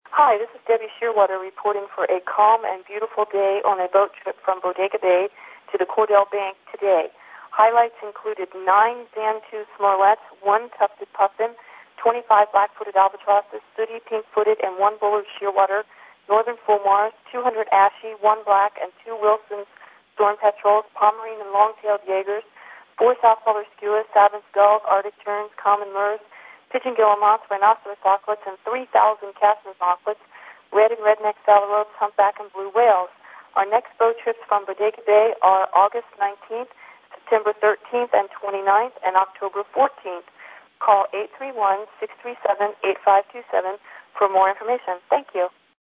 telephone report for August 10, 2002.